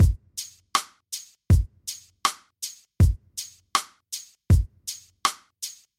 根源雷鬼鼓
描述：雷鬼鼓
Tag: 70 bpm Reggae Loops Drum Loops 2.31 MB wav Key : A